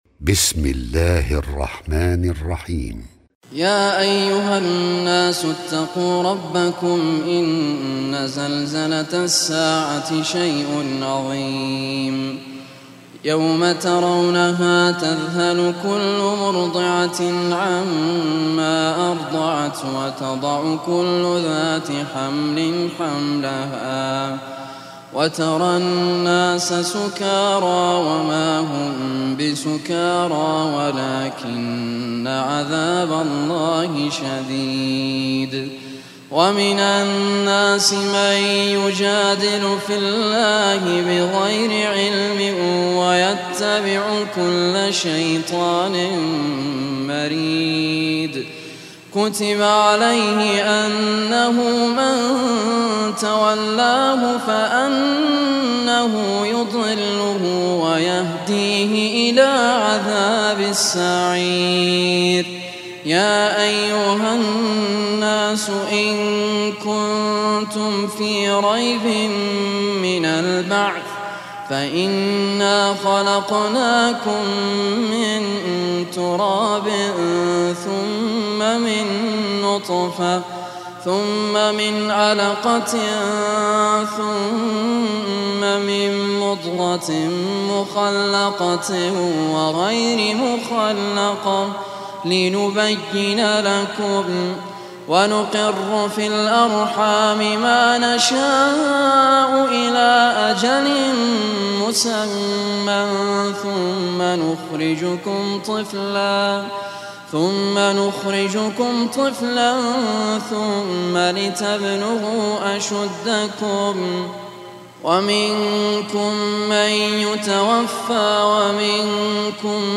Surah Al Hajj Recitation by Raad Al Kurdi
Surah Al Hajj is 22 chapter of Holy Quran. Listen or download beautiful recitation of Surah Al Hajj by Muhammad Raad Al Kurdi.